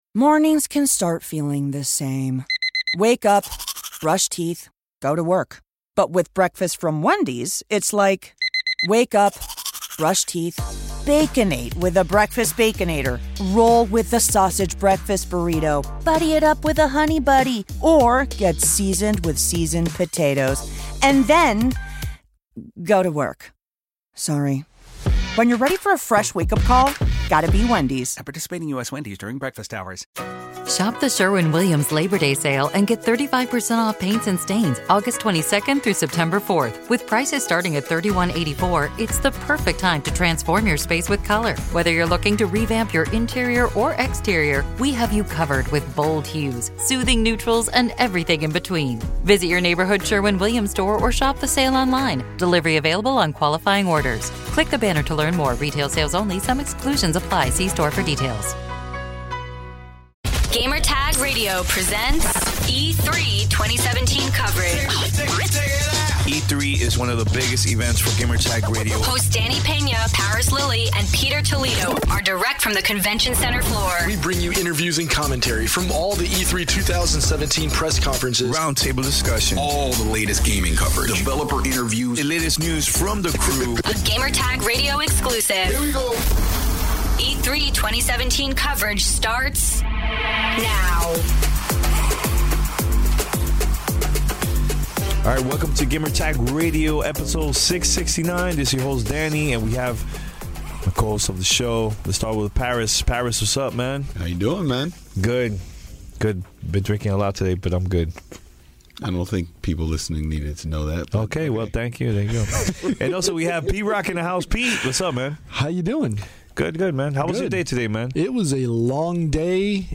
Roundtable discussion